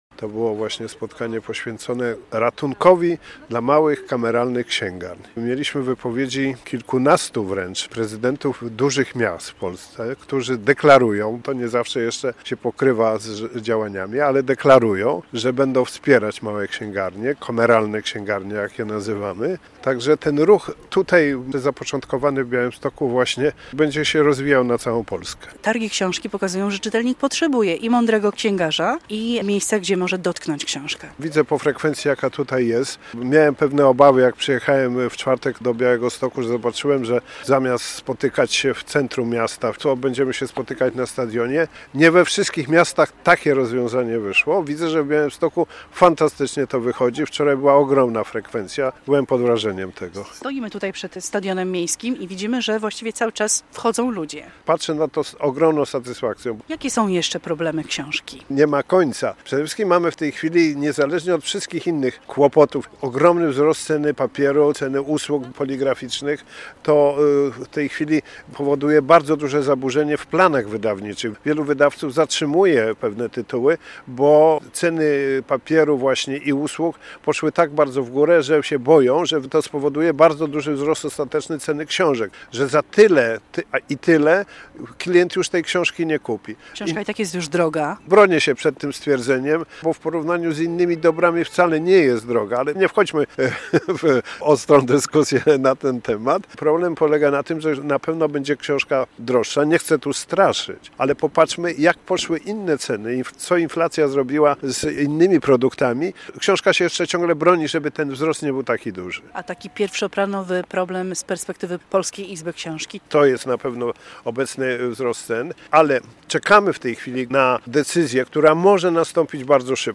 9. Targi Książki i festiwal "Na pograniczu kultur" w Białymstoku